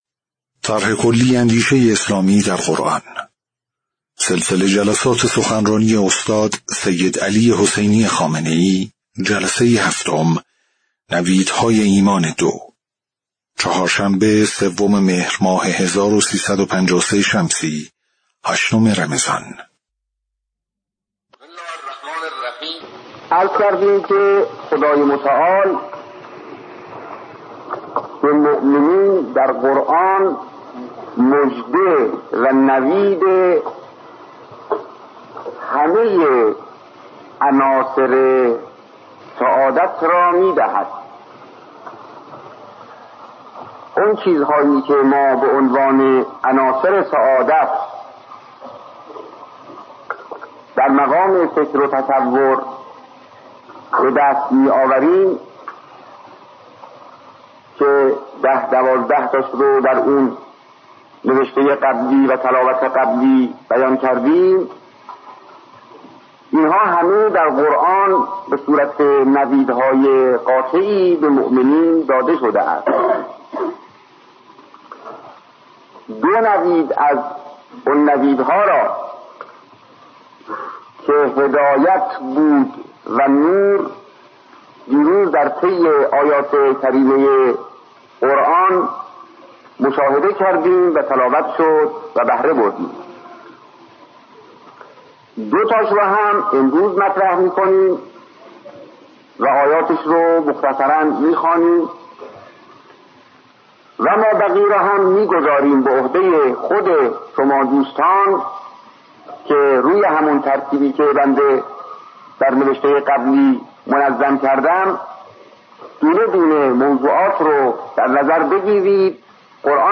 صوت/ جلسه‌ هفتم سخنرانی استاد سیدعلی‌ خامنه‌ای رمضان۱۳۵۳
سخنرانی قدیمی